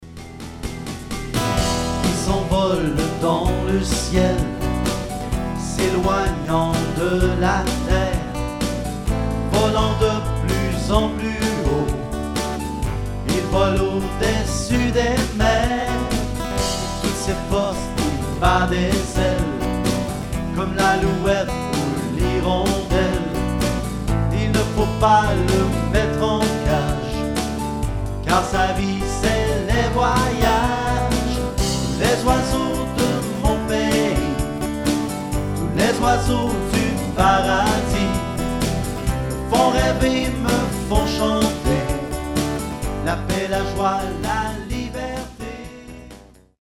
en mimes et en chansons
tout en alternant le mime, la musique et le théâtre.